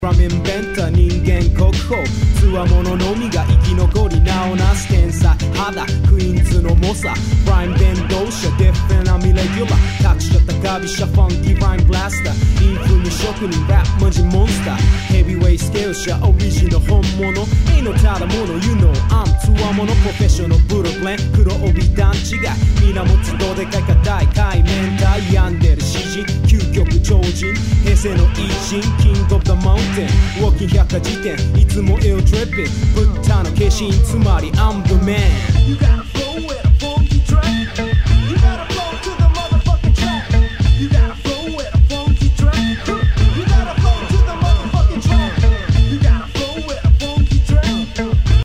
日本語ラップクラシック!!
Tag       HIP HOP Japan